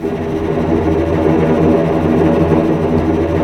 Index of /90_sSampleCDs/Roland L-CD702/VOL-1/STR_Vcs Tremolo/STR_Vcs Trem p